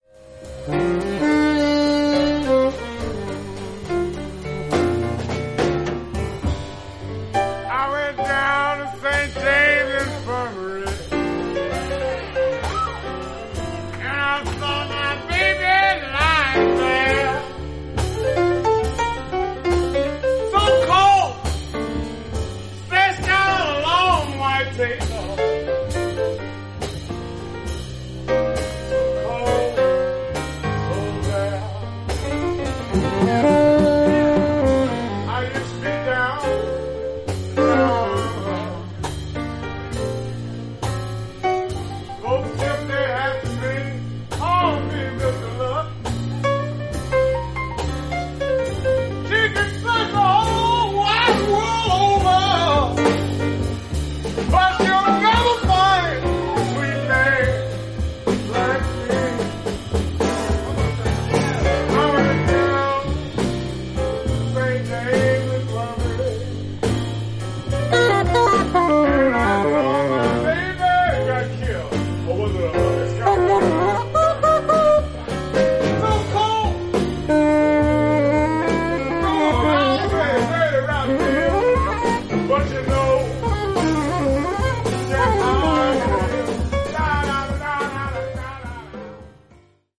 フロリダ出身のジャズ・サックス奏者